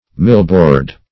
Search Result for " millboard" : Wordnet 3.0 NOUN (1) 1. stout pasteboard used to bind books ; The Collaborative International Dictionary of English v.0.48: Millboard \Mill"board`\ (m[i^]l"b[=o]rd`), n. A kind of stout pasteboard.